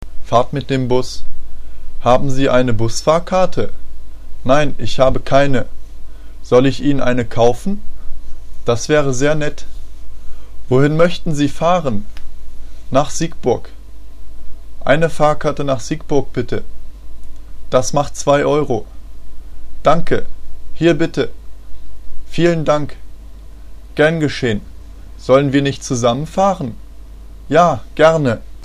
の単語の発音（.mp3)